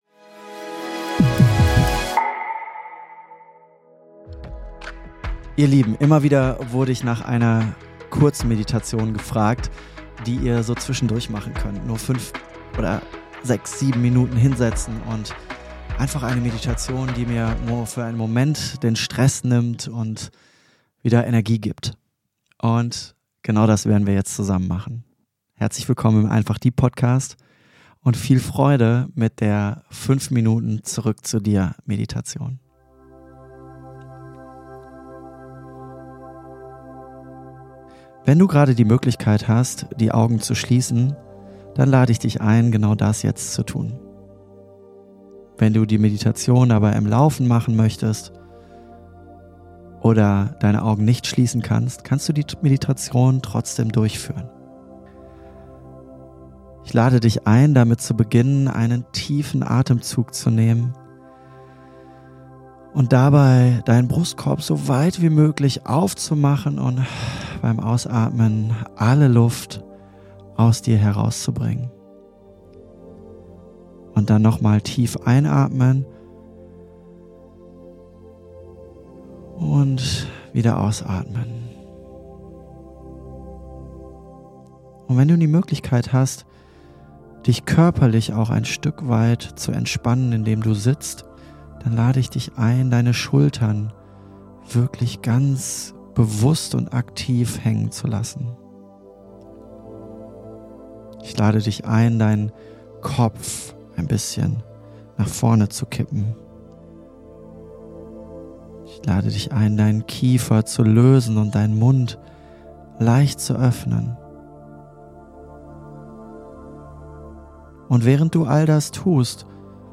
🧘‍♀5 Minuten Kurzmeditation für Pause zwischendurch🧘‍♂ - LIVE MEDITATION 7:09